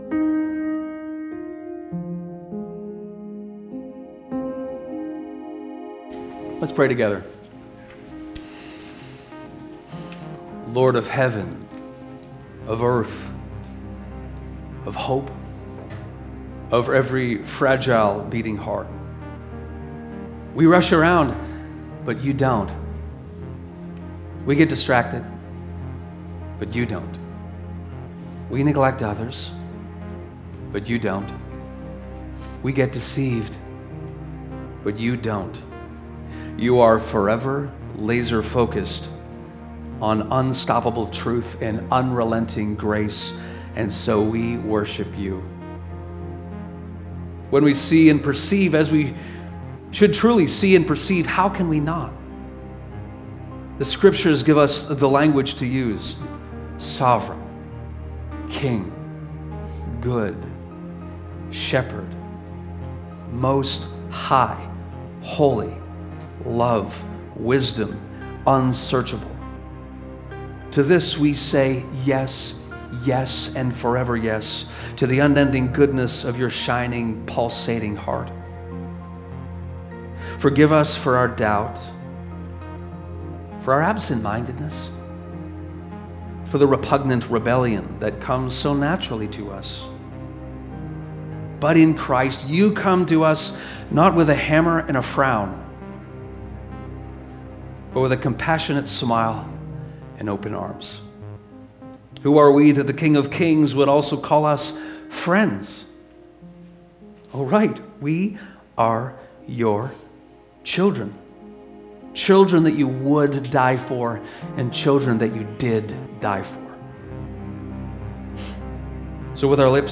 To this we say Yes Yes and Forever Yes - a prayer